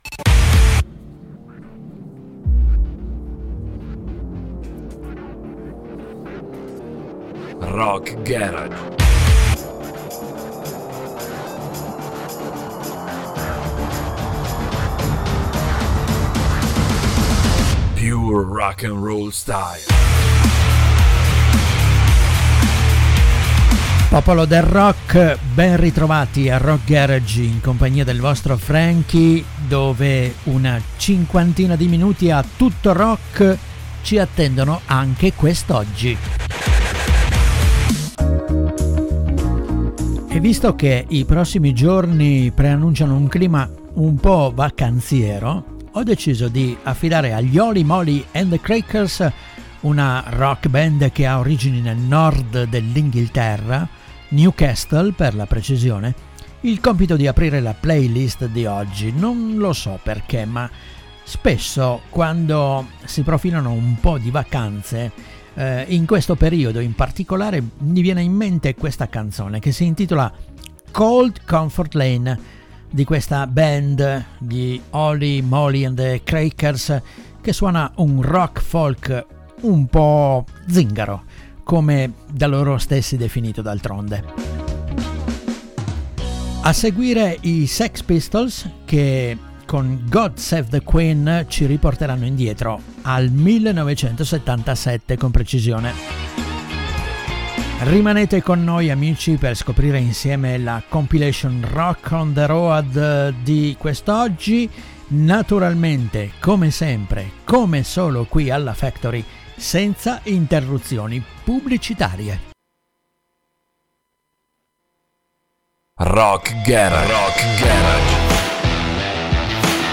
raccolta di canzoni
in stile rock’n’roll